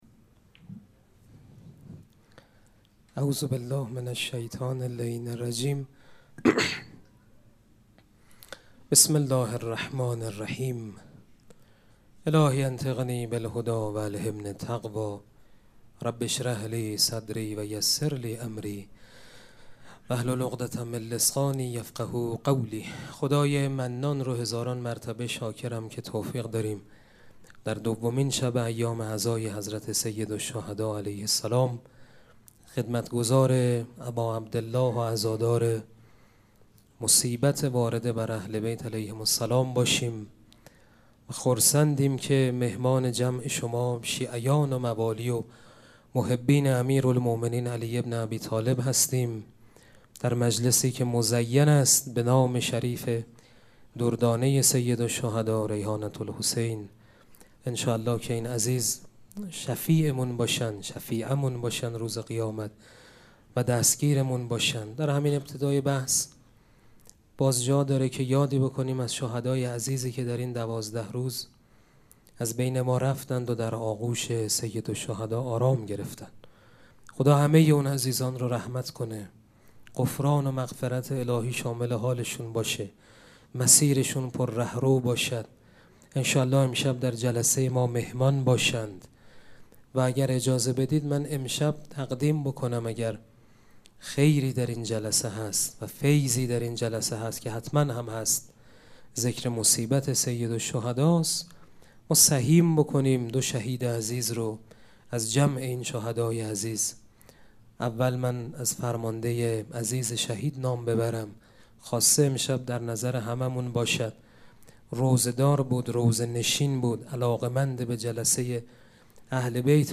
سخنرانی
مراسم عزاداری شب دوم محرم الحرام ۱۴۴۷ پنجشنبه ۶ تیر ماه ۱۴۰۴ | ۱ محرم الحرام ۱۴۴۷ حسینیه ریحانه الحسین سلام الله علیها